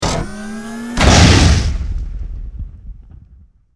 battlepunch.wav